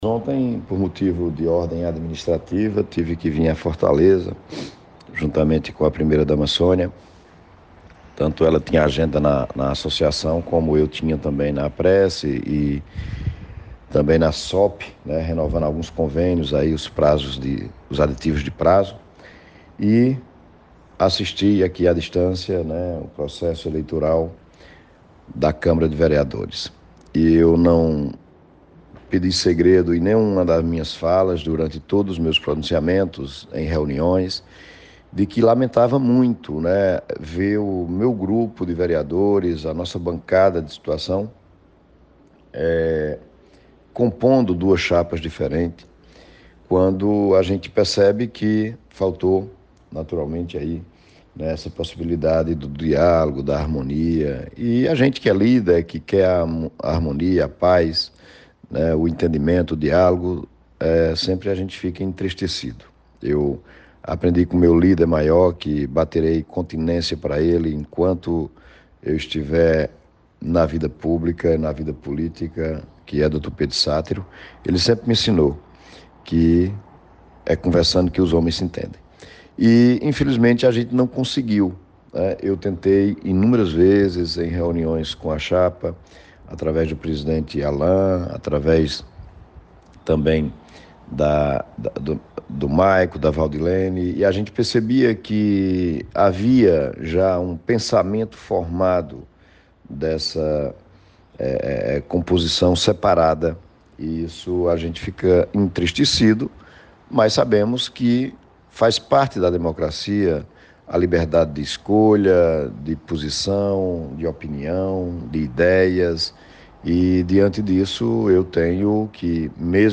O prefeito Zé Helder, MDB, em áudio enviado para a reportagem da Rádio Cultura [FM 96.3], que acata resultado da eleição da mesa diretora da Câmara Municipal de Vereadores de Várzea Alegre.